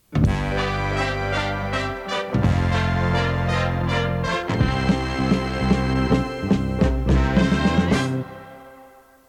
Indicatiu instrumental